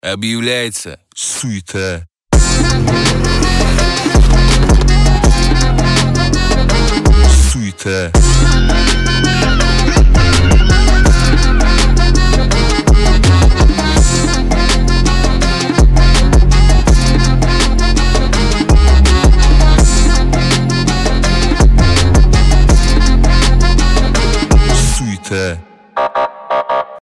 • Качество: 320, Stereo
громкие
мощные басы